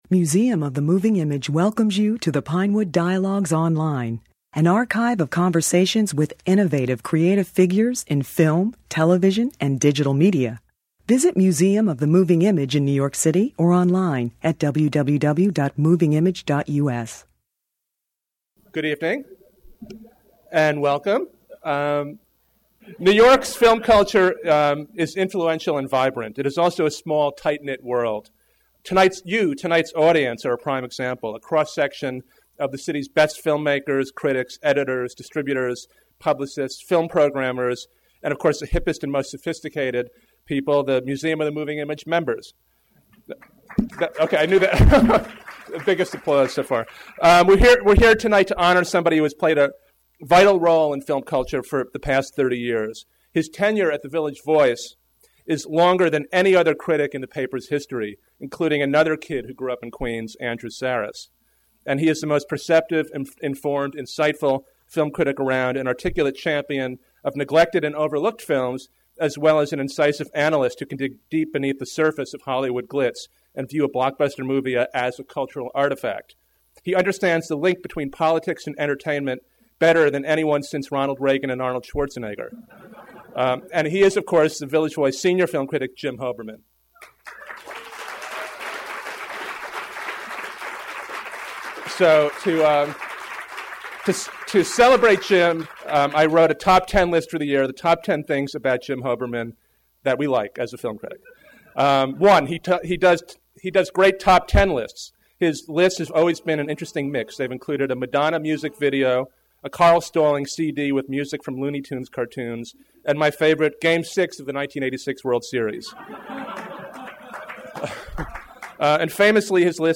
J. Hoberman + A. O. Scott January 5, 2008 J. Hoberman is one of the world's most erudite and perceptive film critics, with writings that combine deep historical knowledge with an expansive view of cinema. To celebrate his 30th anniversary as a film critic at The Village Voice , the Museum of the Moving Image presented a program including a conversation with Hoberman moderated by New York Times film critic A. O. Scott.